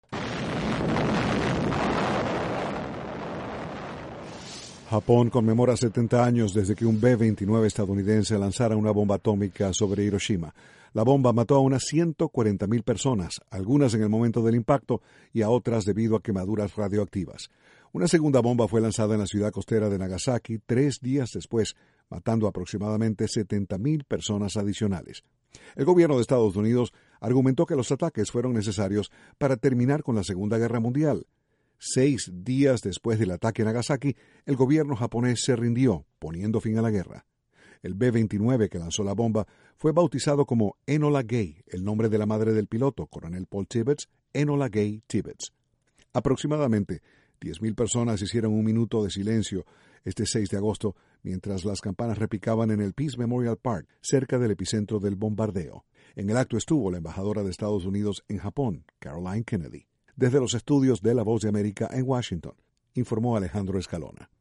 Se conmemora el septuagésimo aniversario de la destrucción de Hiroshima. Desde la Voz de América, Washington